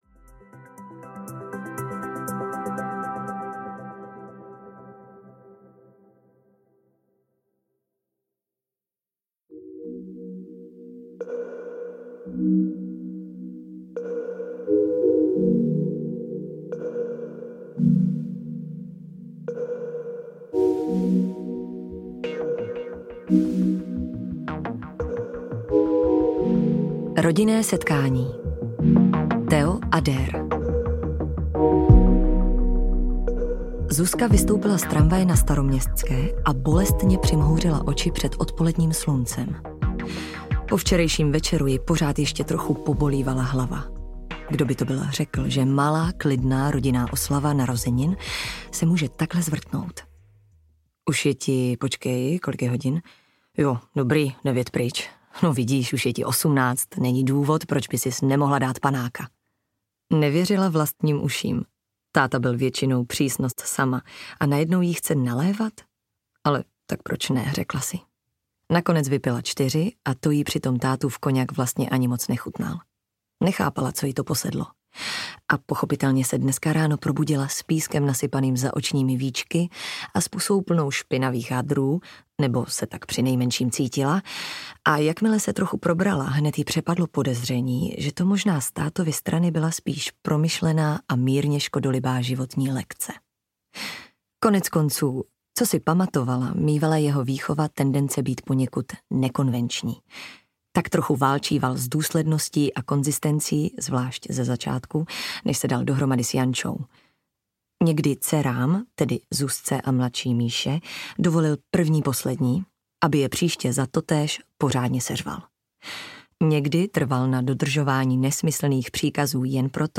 Všechny barvy duhy audiokniha
Ukázka z knihy